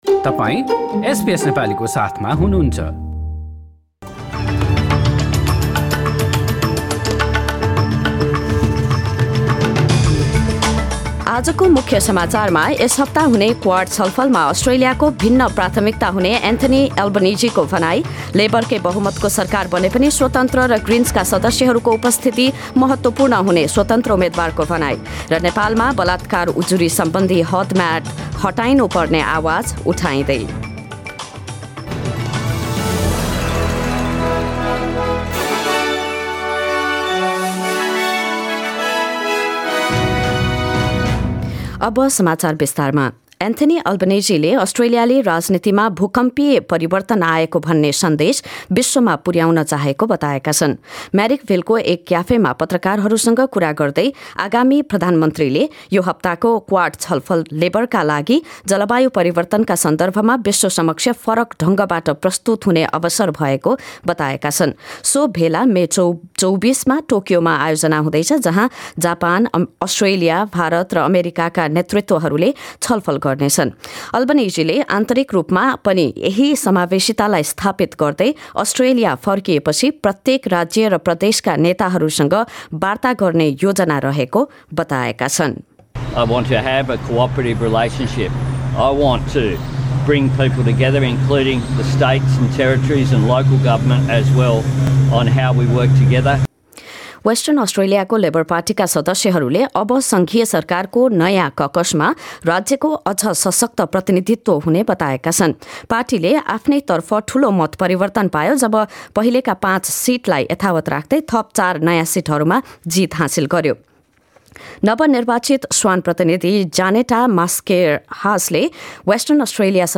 एसबीएस नेपाली अस्ट्रेलिया समाचार: आइतबार २२ मे २०२२